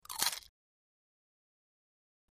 BiteManyPotatoChip PE390706
Bite Into Many Potato Chips, X7